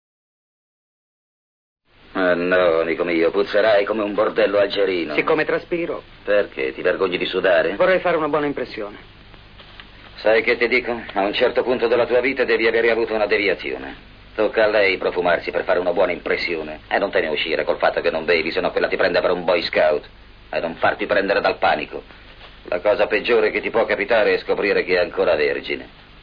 voce di Paolo Ferrari nel film "Provaci ancora Sam", in cui doppia Jerry Lacey.